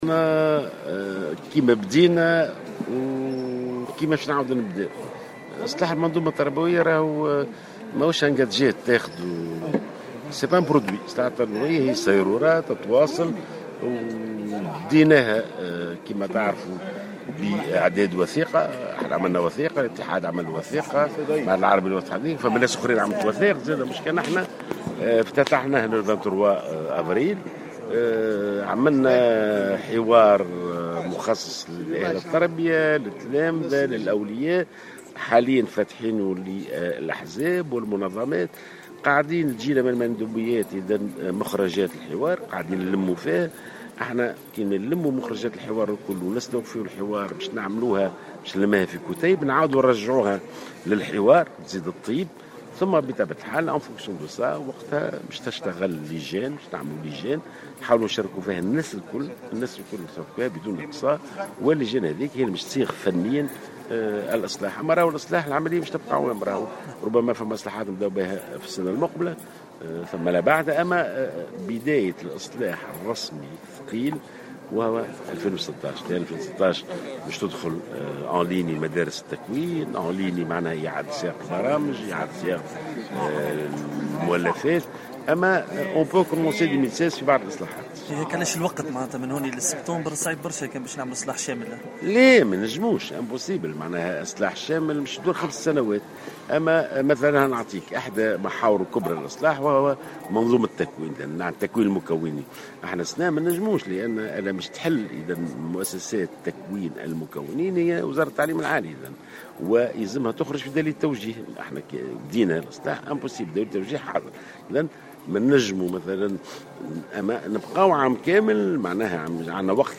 أكد وزير التربية ناجي جلول في تصريح للجوهرة اف ام امس السبت عقب زيارته للمنستير ان الاعلان عن نتائج مناظرة "السيزيام" سيكون بعد عيد الفطر مباشرة مضيفا ان الاصلاح الفعلي للمنظومة التربوية سينطلق مع بداية سنة 2016.